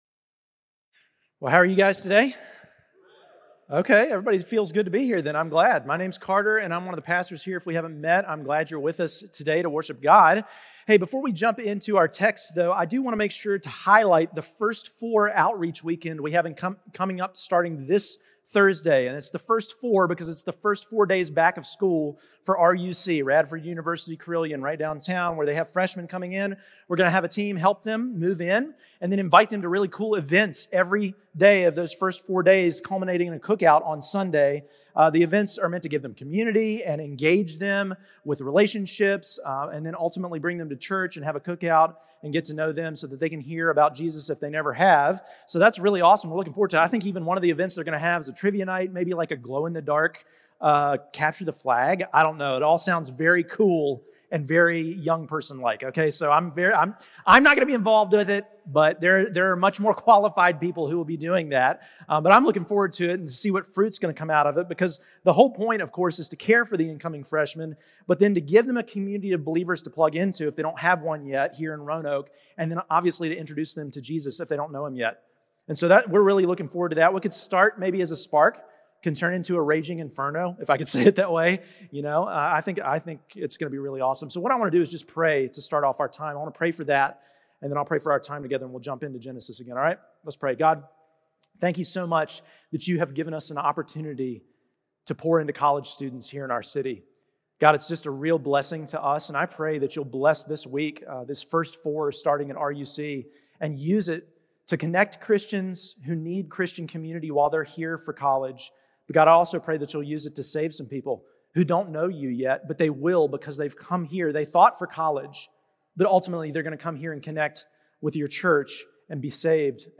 Redemption Church Sermons Unlock The Hidden Power of Prayer Aug 17 2025 | 00:51:34 1x 00:00 / 00:51:34 Subscribe Share Apple Podcasts Spotify Overcast RSS Feed Share Link Embed